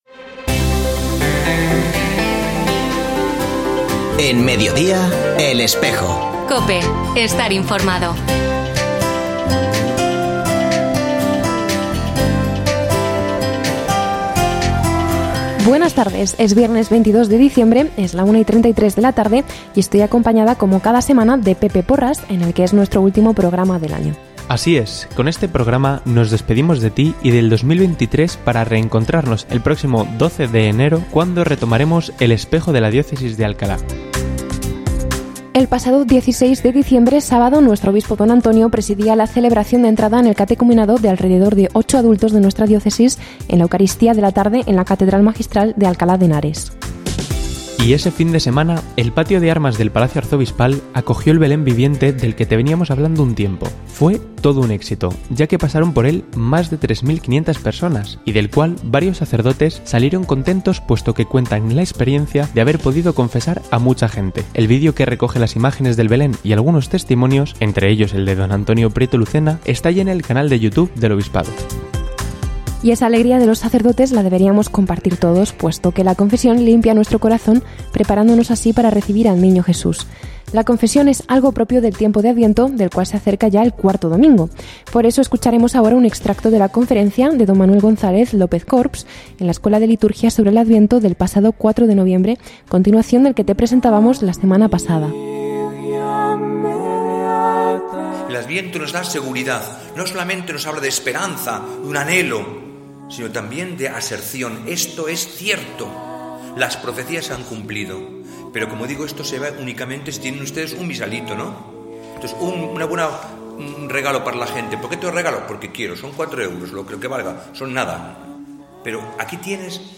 Ofrecemos el audio del programa de El Espejo de la Diócesis de Alcalá emitido hoy, 22 de diciembre de 2023, en radio COPE. Este espacio de información religiosa de nuestra diócesis puede escucharse en la frecuencia 92.0 FM, todos los viernes de 13.33 a 14 horas.